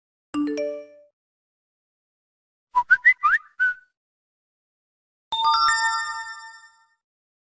who remembers these old smartphone sound effects free download
who remembers these old smartphone notification sounds?